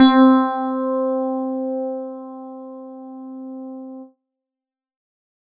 EP_middle_C.wav